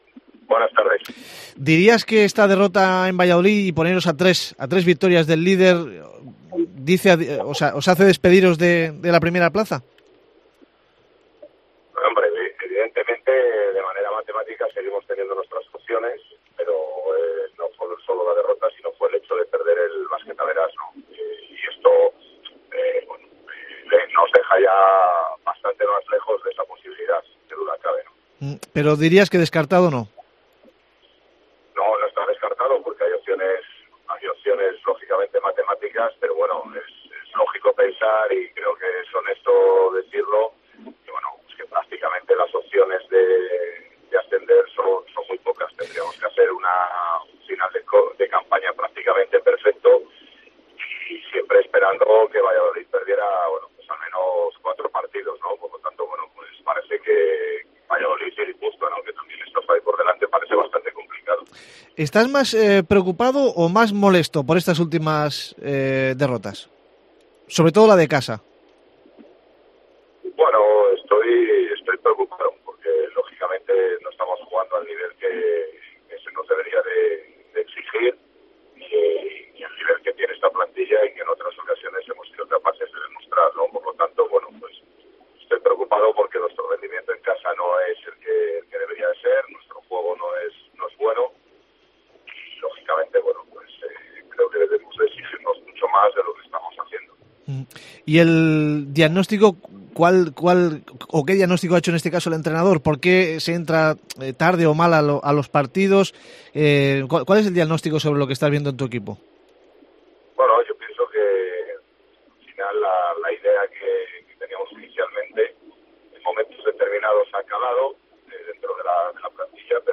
entrevista a Deportes Cope Mallorca